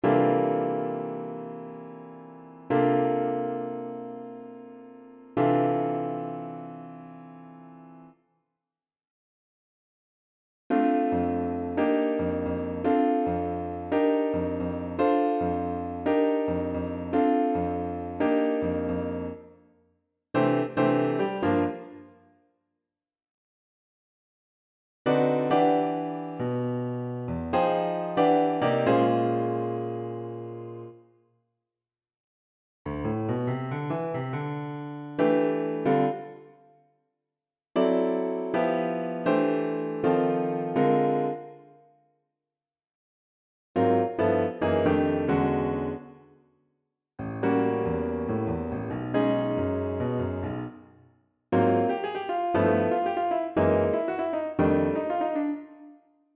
Diatonic Chord Extensions | Jazz For Piano
Upper structure diatonic chord extensions add colour to your voicings by using unaltered scale tones.